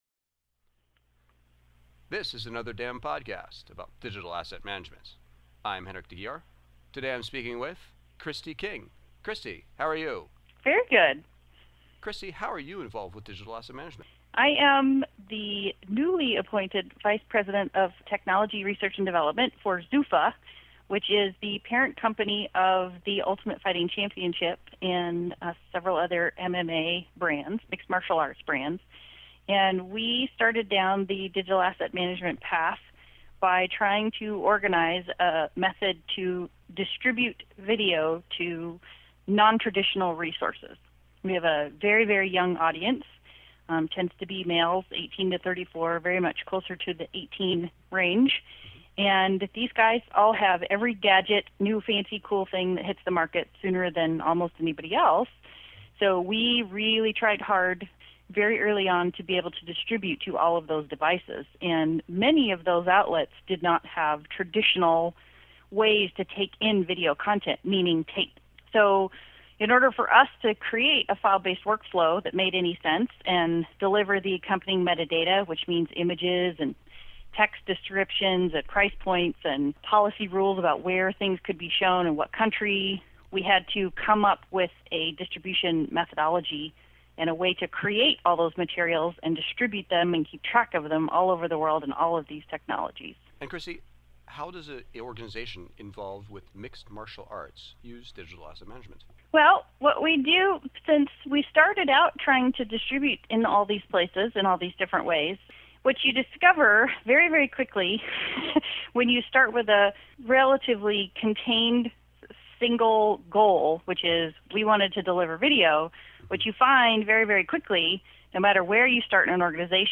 Here are the questions asked: